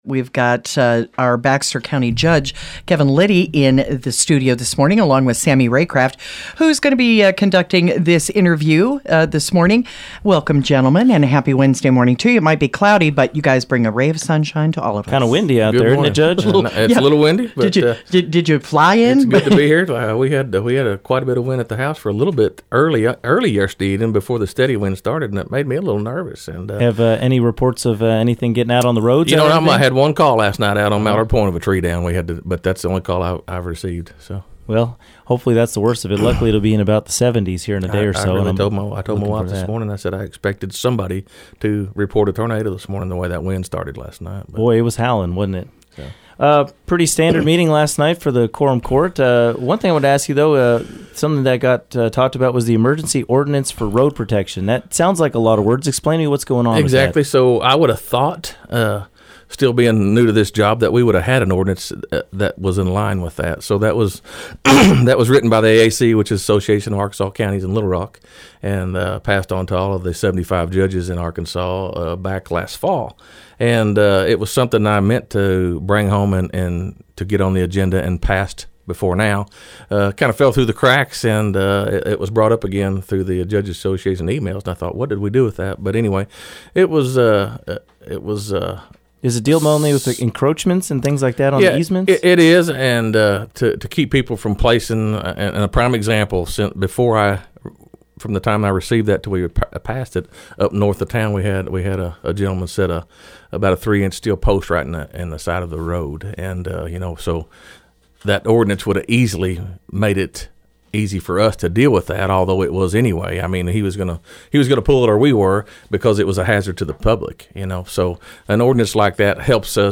Baxter County Judge Kevin Litty stopped by KTLO, Classic Hits and the Boot News to discuss the latest after Tuesday's Quorum Court meeting.